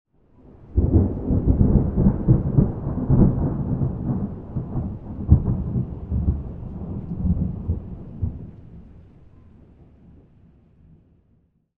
thunderfar_16.ogg